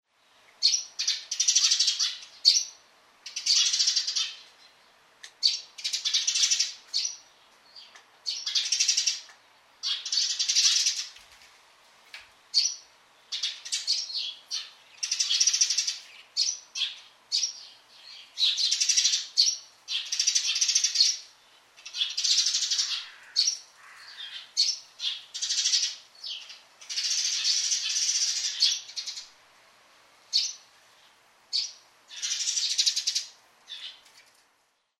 suzume_c1.mp3